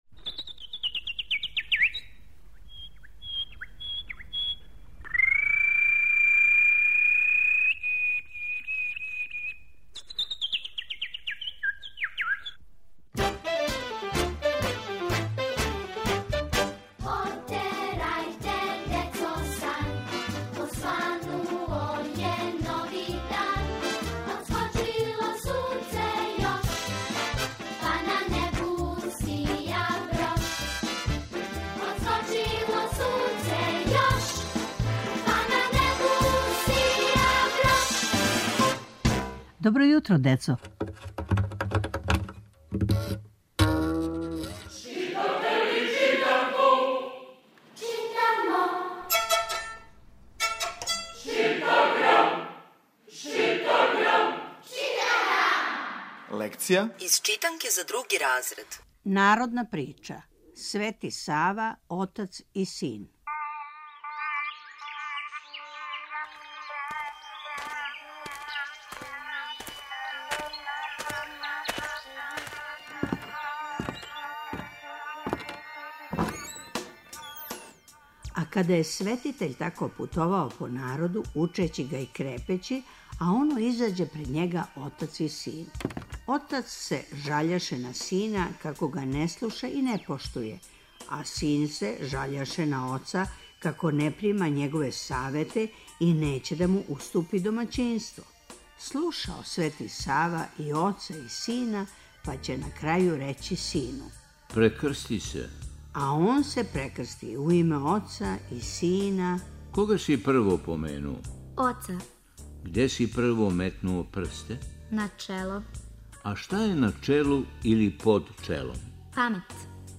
Сваког понедељка у емисији Добро јутро, децо - ЧИТАГРАМ: Читанка за слушање. Ове недеље - други разред, лекција: "Свети Сава, отац и син", народна прича.